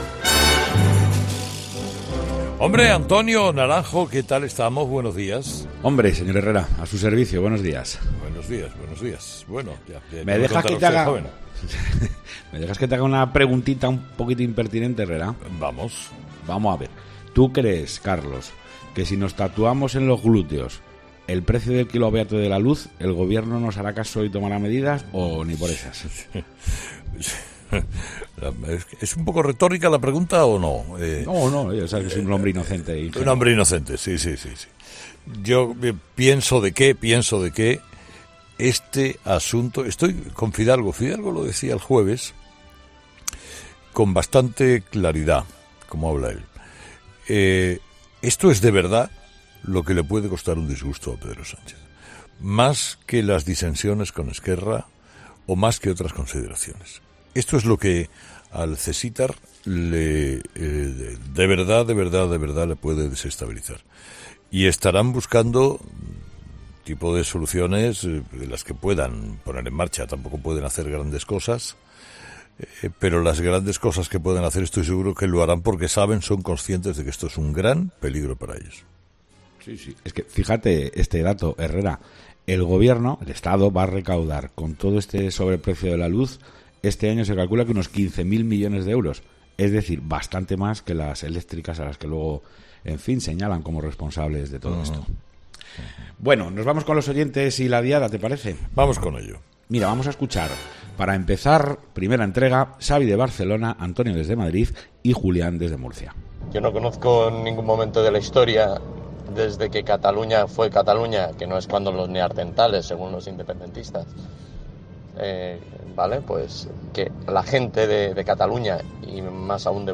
La tertulia de los oyentes
De lunes a jueves, los oyentes formulan además la ya mítica “Preguntita al Herrera”, sobre los temas más imprevisibles, solemnes y cómicos, respondidos por el comunicador con la agilidad acostumbrada.